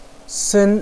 sen1.wav